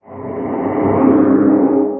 guardian_idle4.ogg